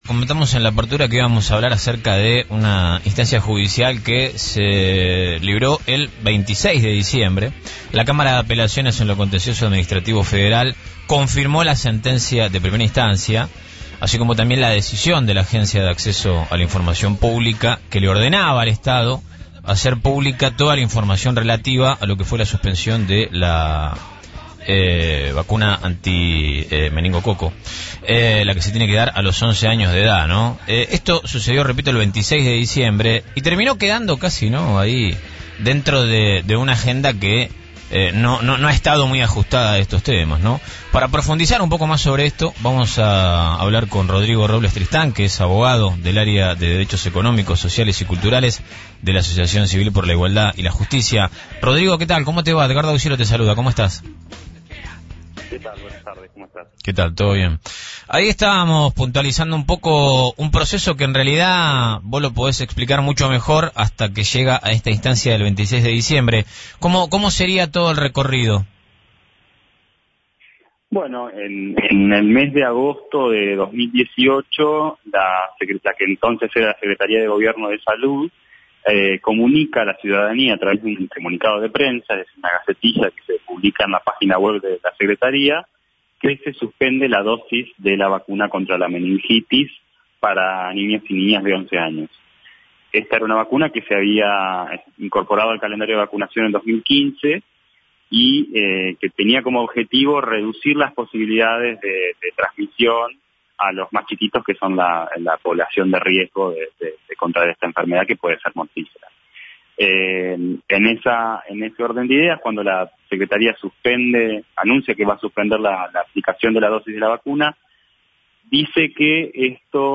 En caso de no existir argumentos, sostuvo, en diálogo con FRECUENCIA ZERO , que tendrá que emprender los mecanismos de investigación para conocer las responsabilidades del caso.